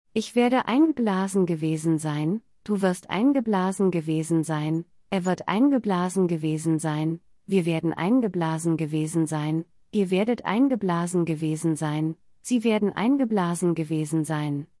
/ˈaɪnˌblaːzən/ · /blɛːst aɪn/ · /bliːs aɪn/ · /ˈbliːzə aɪn/ · /ˈaɪnˌɡəˈblasən/